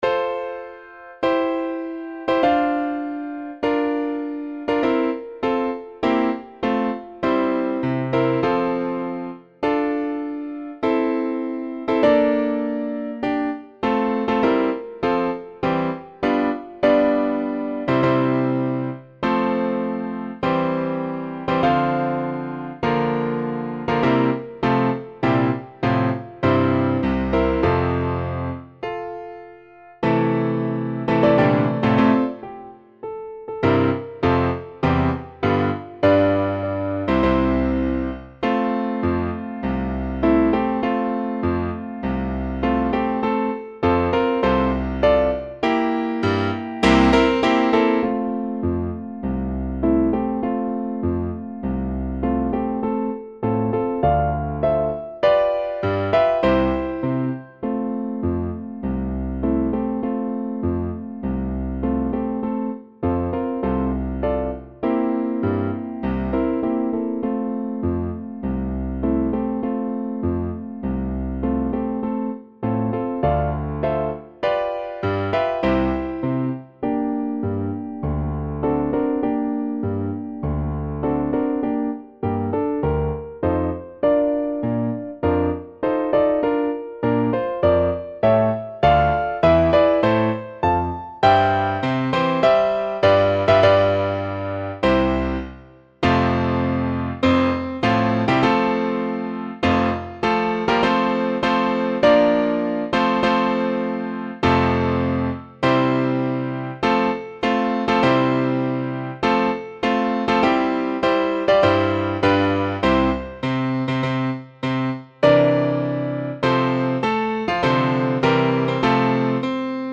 タン、タタン、タターン 陽気に Vatican http